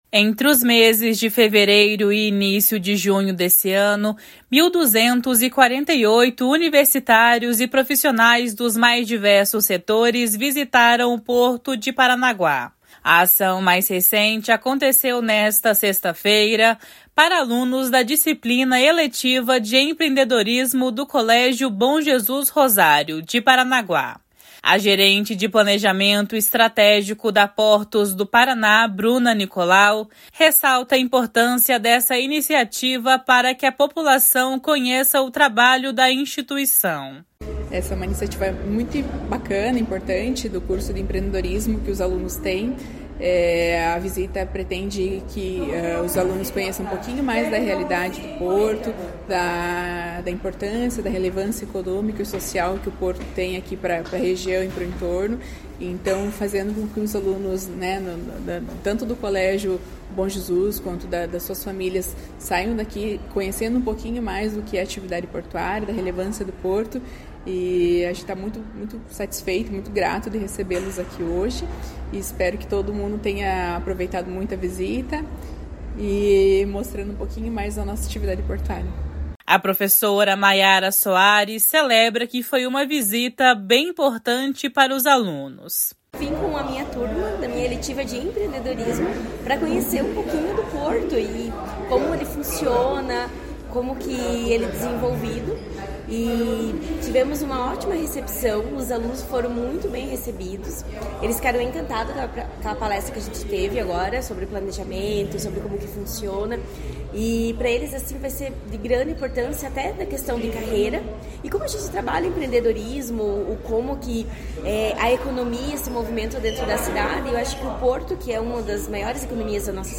A visitação é gratuita e ocorre de segunda a sexta-feira, entre nove horas da manhã e quatro da tarde, e deve ser agendada com antecedência. (Repórter: